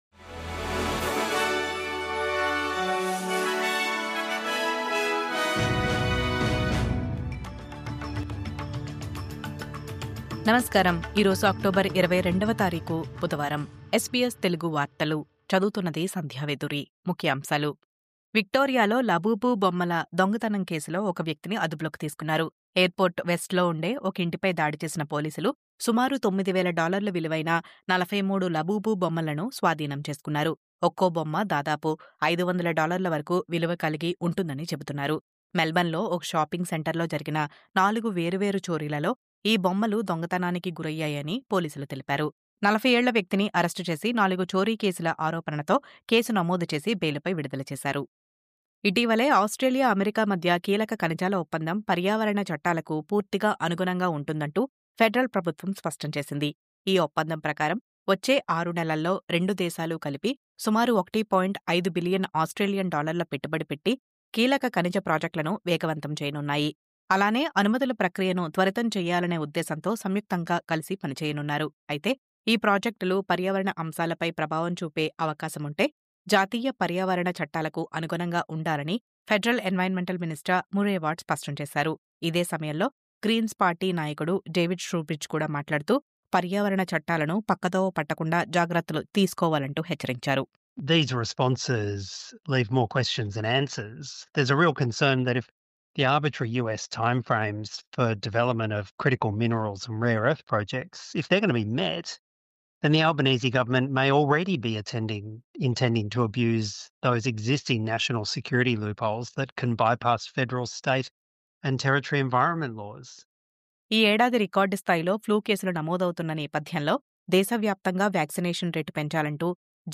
నమస్కారం .. ఈ రోజు ముఖ్యాంశాలు..